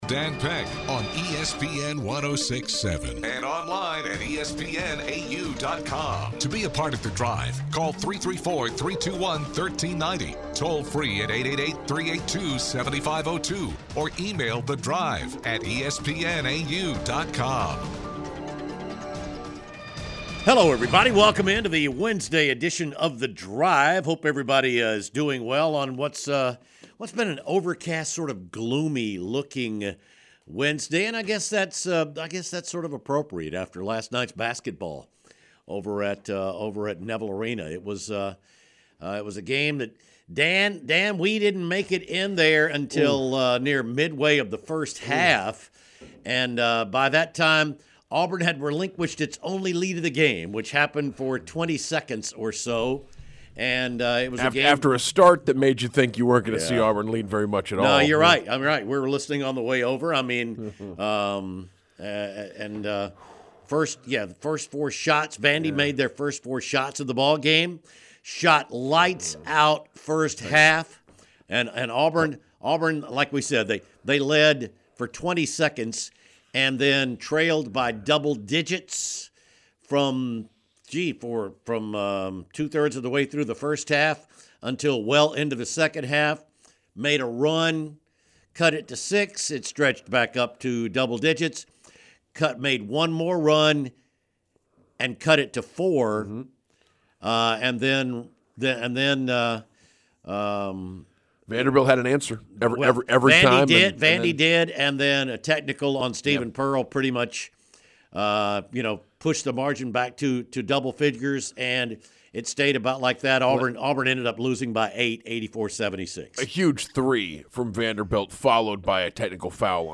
Callers wonder about officiating and personnel choices made by Auburn's coaches.